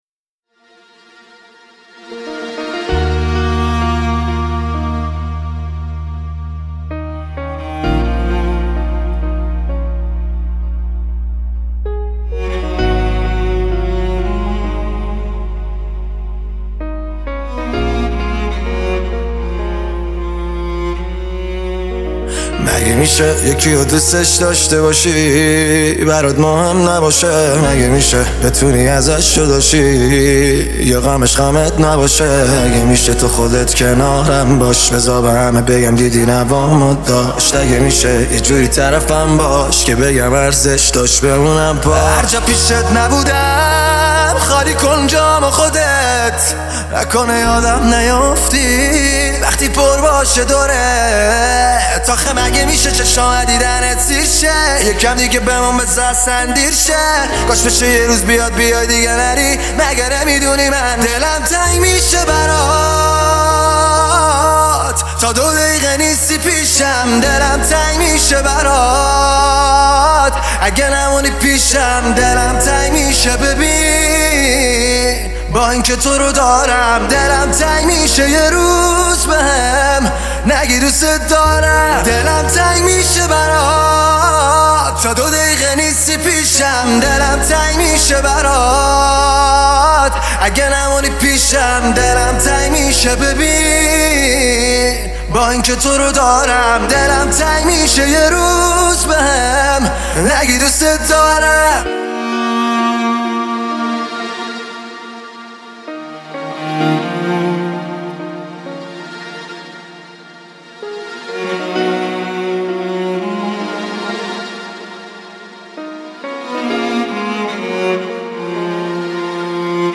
پاپ عاشقانه عاشقانه غمگین